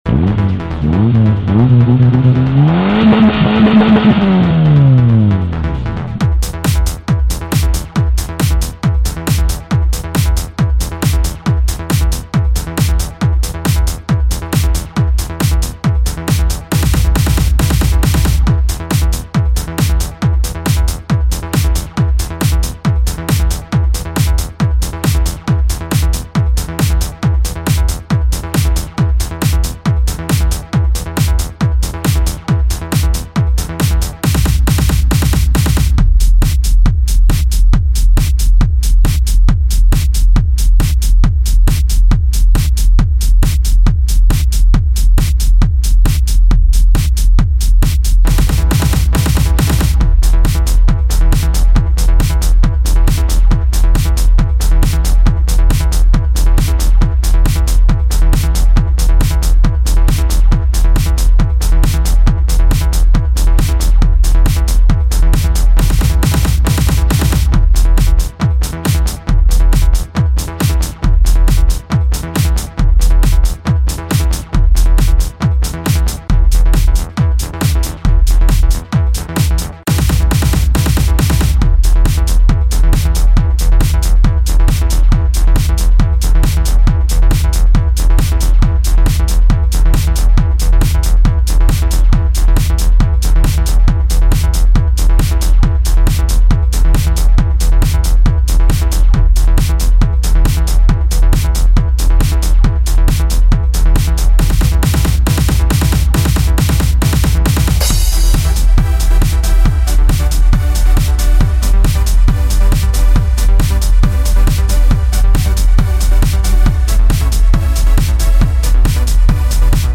Žánr: Indie/Alternativa